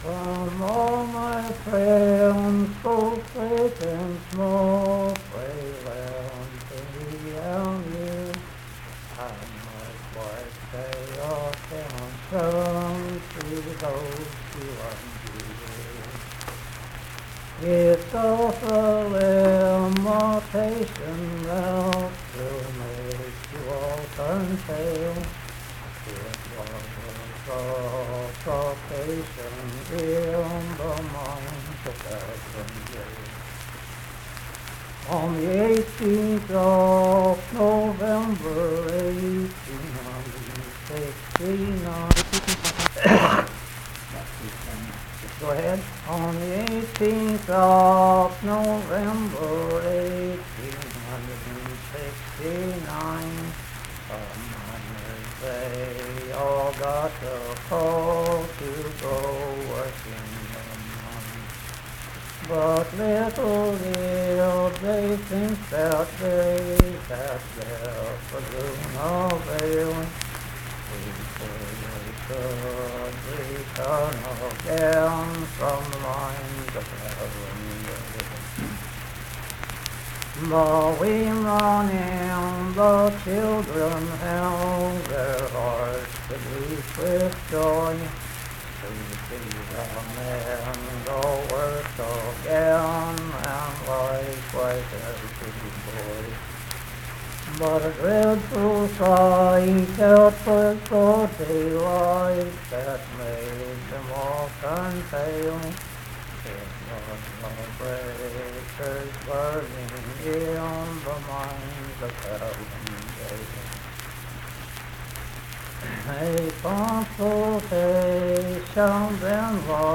Unaccompanied vocal music
Voice (sung)
Mannington (W. Va.) , Marion County (W. Va.)